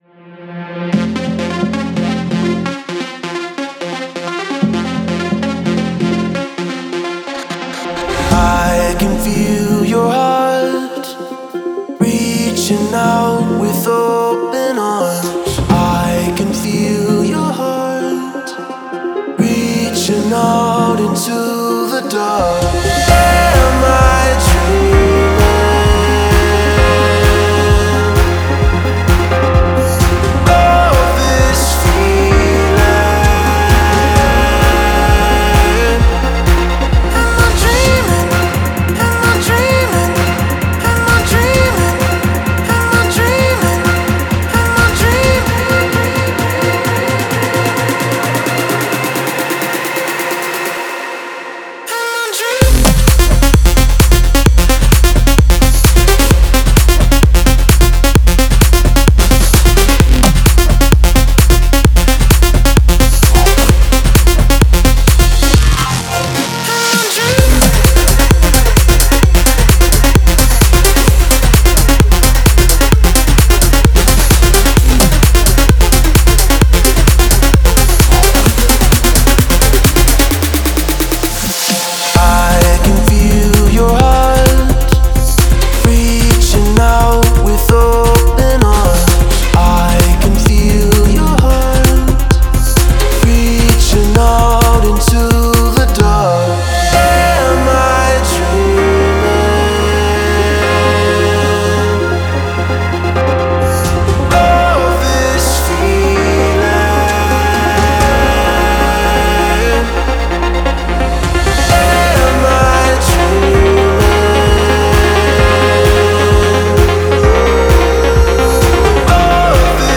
это завораживающая композиция в жанре электронной музыки